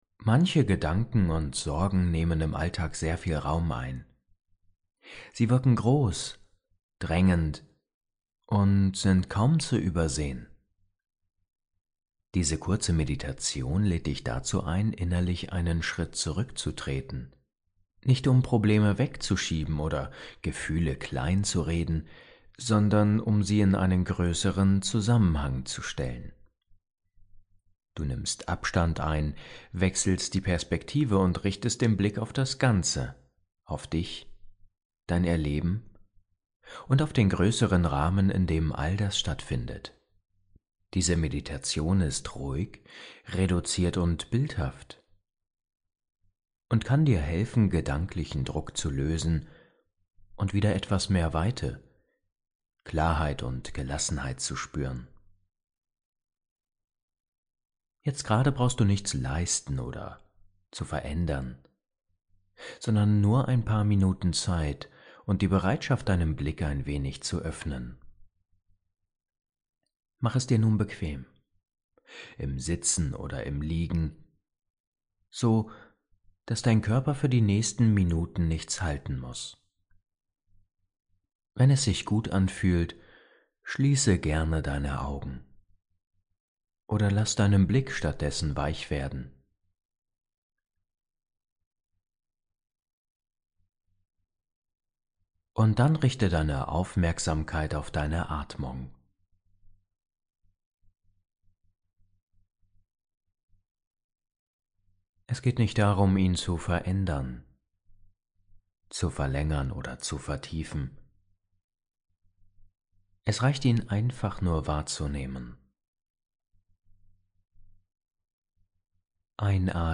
Ruhig.